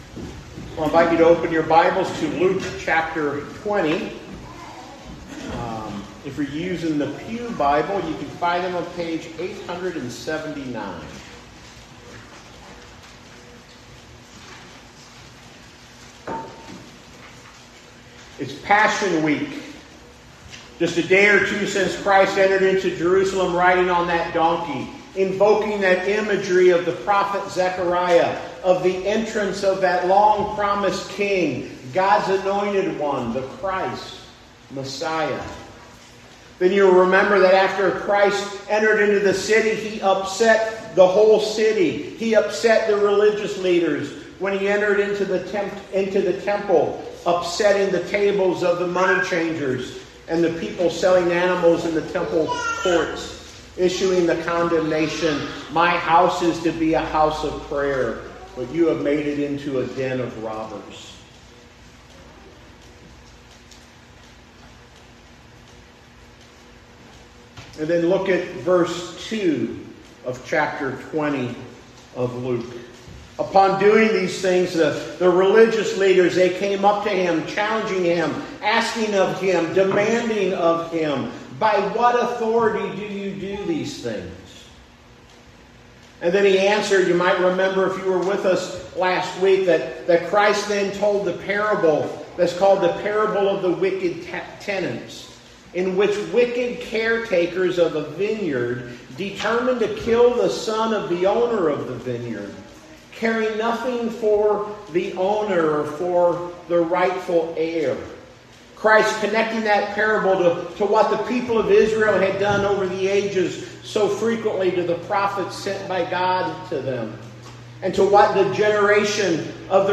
Sermon on Luke 20:19 – 44, New Port Presbyterian Church